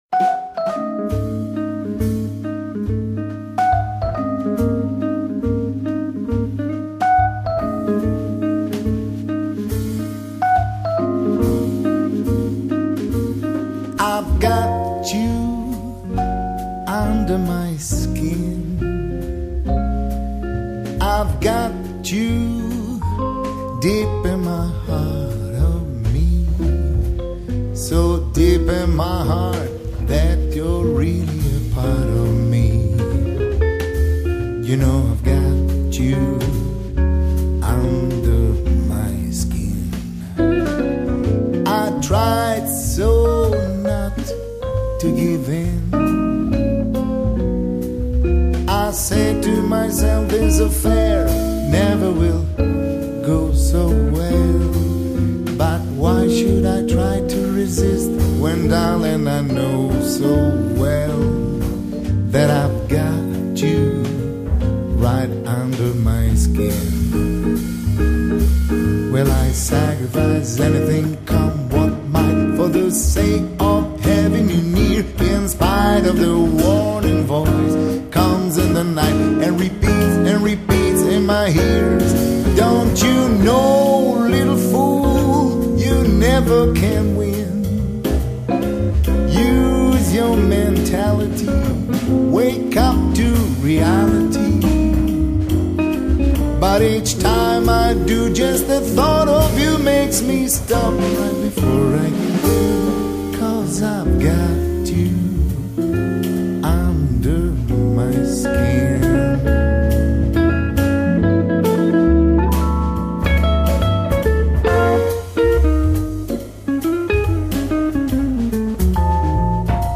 类别： 爵士
艺人：多人演出
主奏乐器：钢琴
以钢琴、鼓、贝斯、吉他的四重奏组合